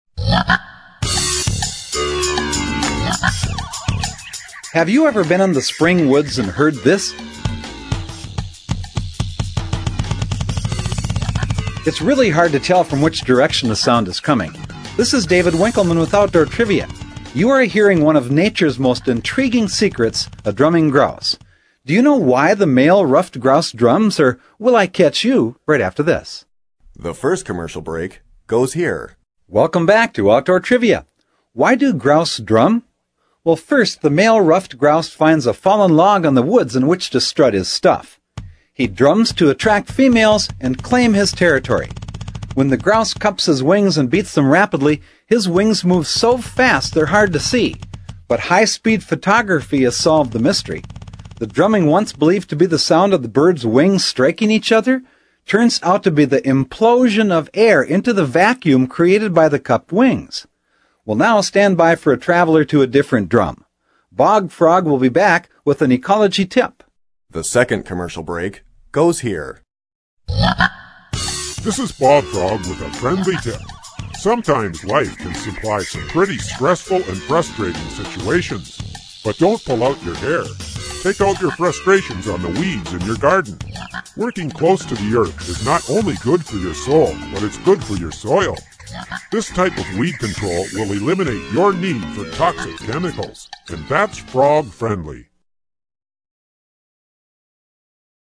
Tune in and play “Name that bird call!” Unlock the enigmas behind the sounds produced by gamebirds.
In fact, the question and answer trivia format of this program remains for